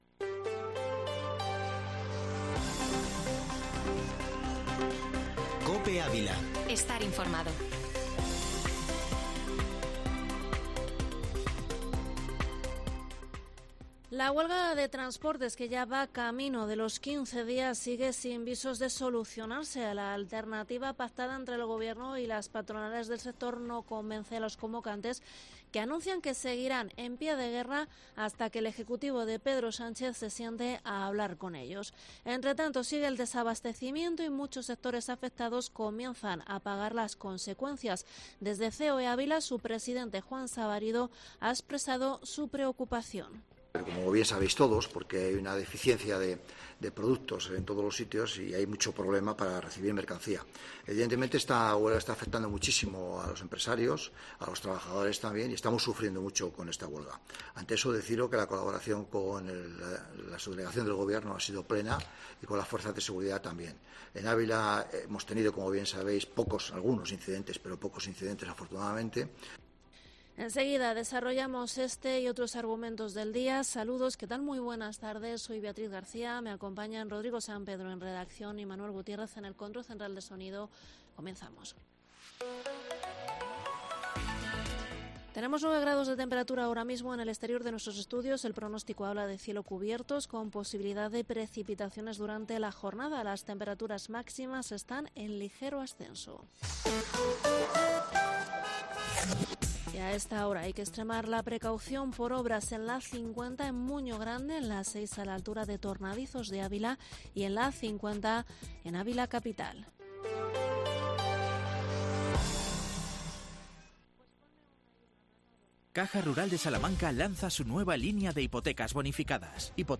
Informativo Mediodía COPE en ÁVILA 25/3/22